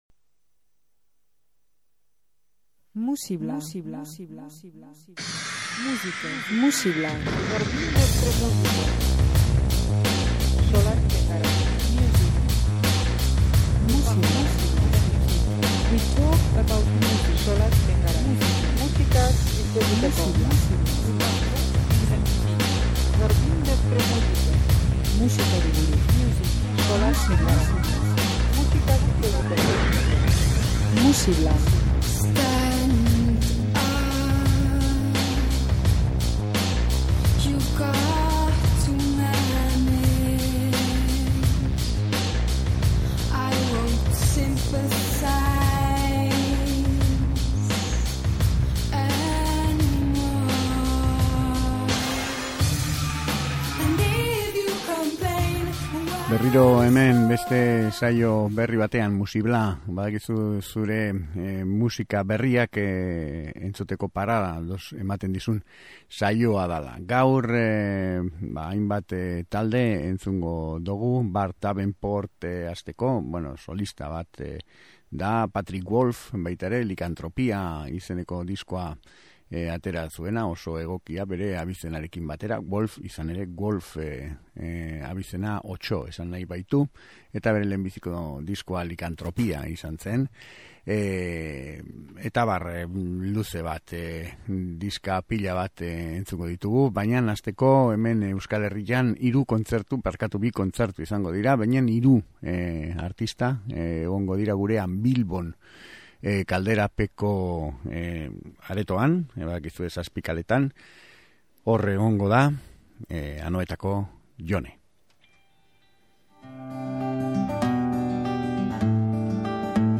Soinu leunak, intentzioz beteta.
Pop melodramatikoaren erakusle bikainena.